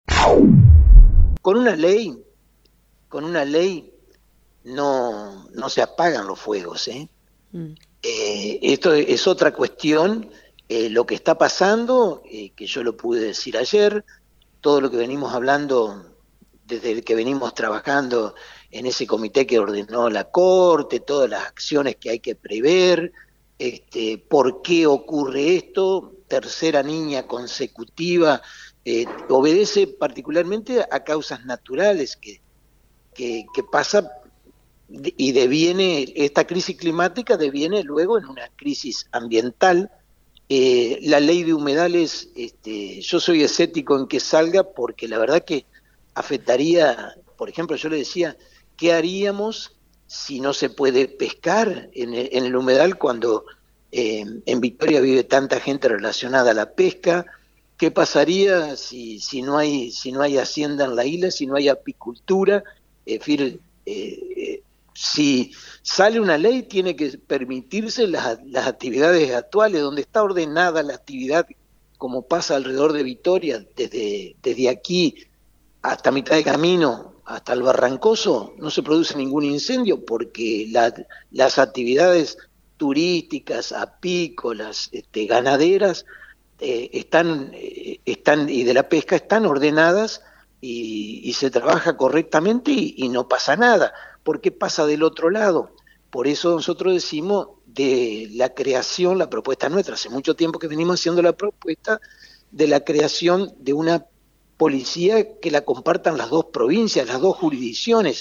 El Intendente en Fm 90.3 ratificó su idea de que una Ley de Humedales no va a ser significativa para solucionar un problema que es de origen natural y ambiental, y volvió a proponer la creación de una policía interjurisdiccional.